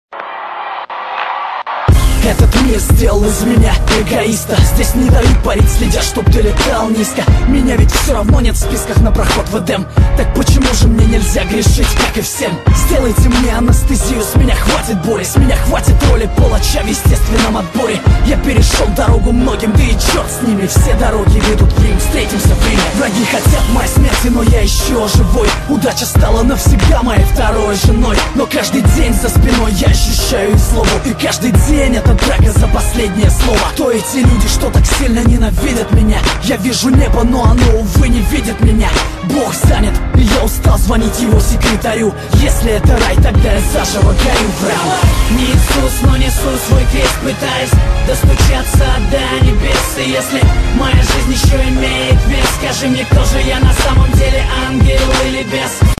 • Качество: 128, Stereo
русский рэп
саундтреки